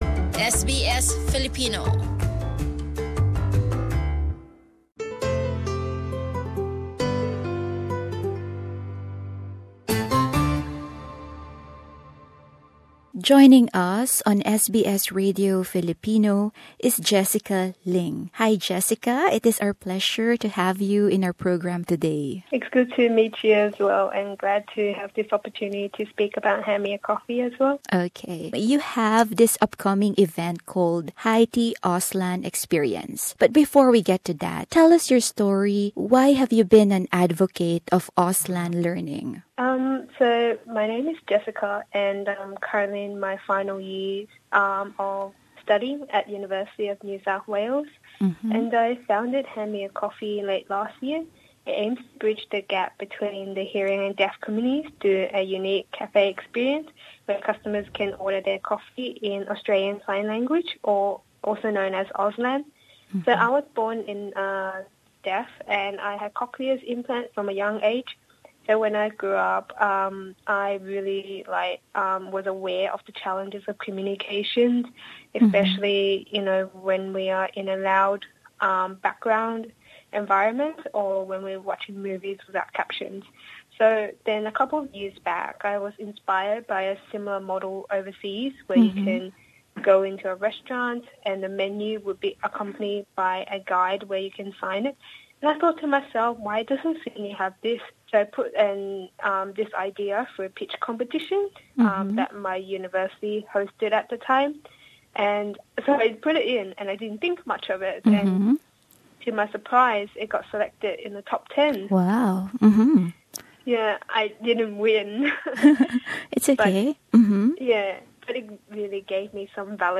Ends Verbatim Transcription of the interview CD